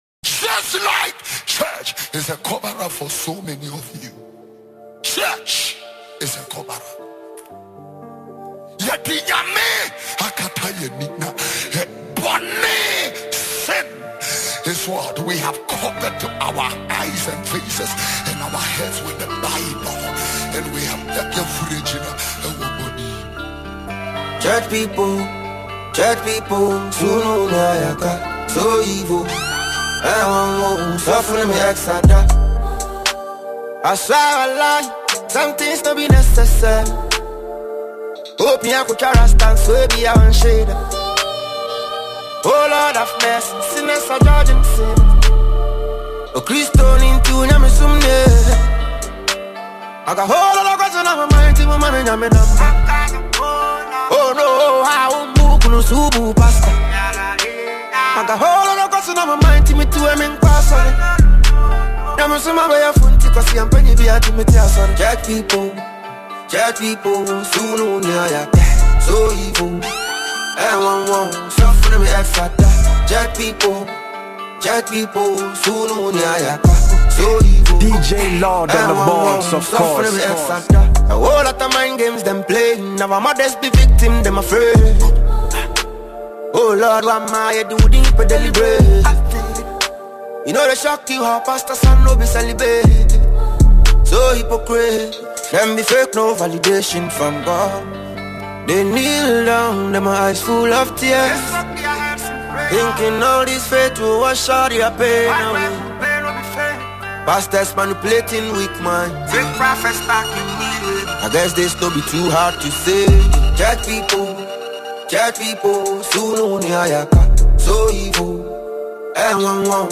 Genre: Mixtape